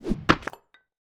axe.wav